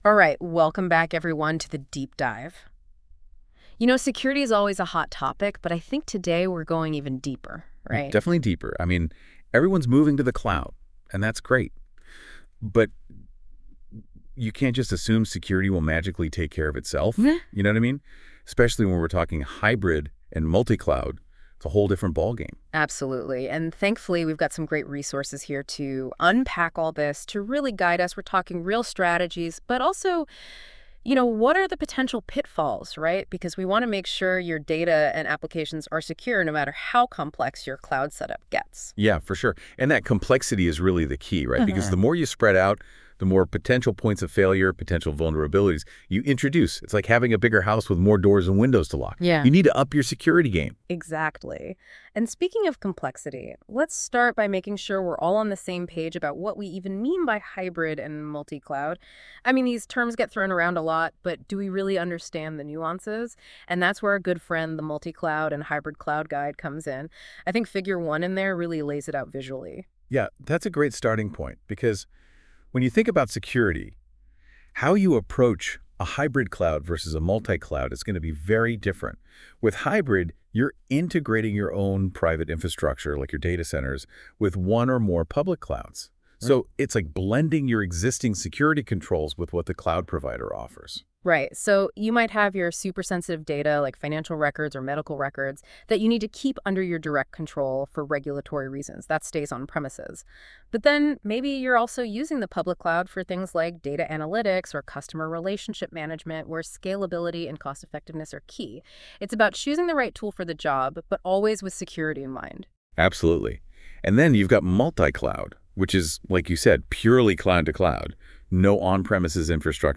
Rather than reading it all, you upload the document to NotebookLM and generate an Audio Overview . NotebookLM will summarise the key points, highlighting important sections, such as deployment strategies and security considerations.